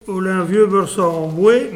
Localisation Nieul-sur-l'Autise
Catégorie Locution